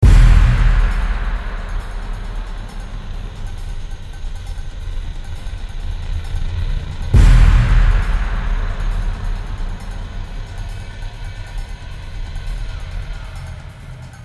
描述：投票结果正在为芬兰总统Urho Kekkonen大声朗读。